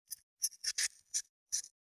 500大根の桂むきの音切る,包丁,厨房,台所,野菜切る,咀嚼音,ナイフ,調理音,
効果音厨房/台所/レストラン/kitchen食材